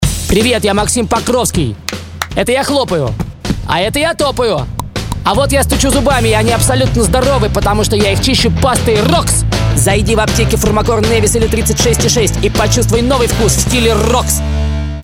Вид рекламы: Радиореклама